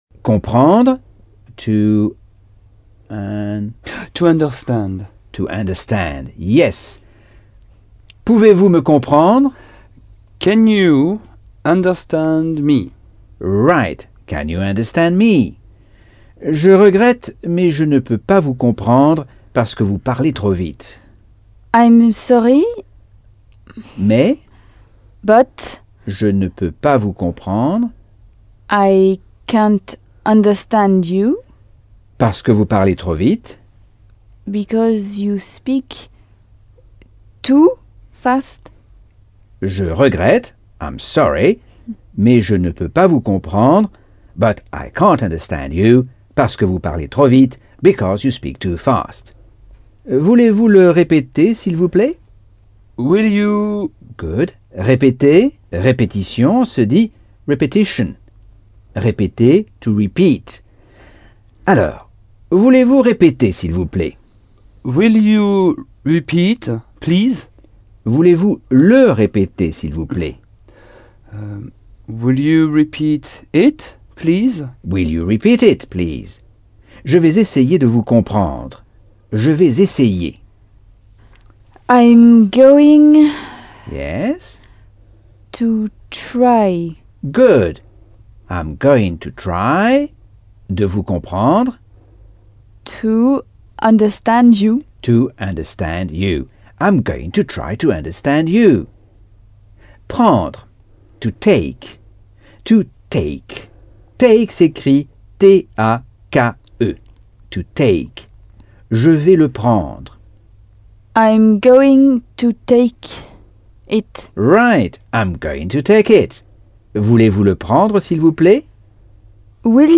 Leçon 4 - Cours audio Anglais par Michel Thomas